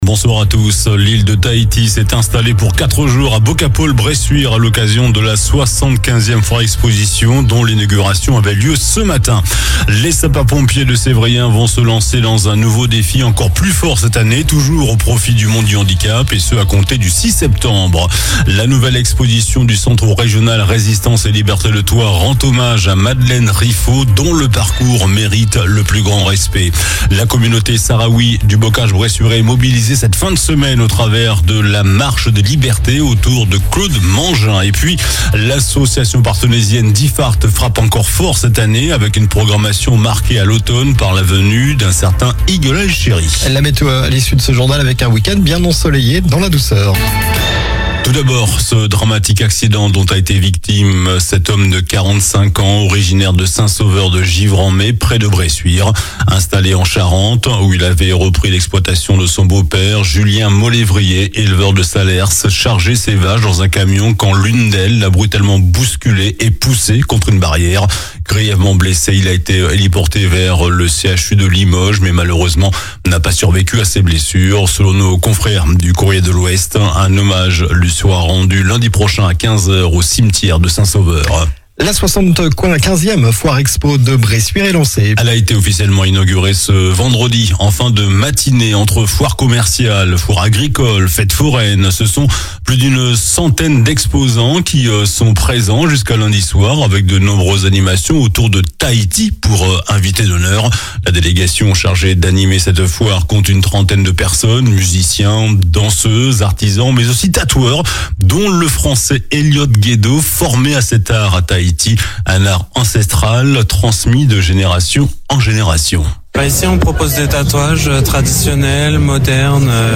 JOURNAL DU VENDREDI 04 avril ( SOIR )